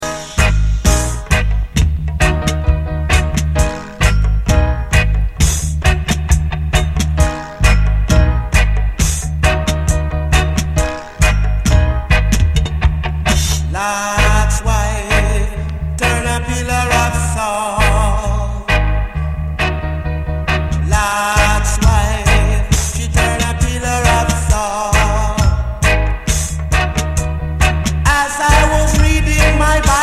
Reggae Ska Dancehall Roots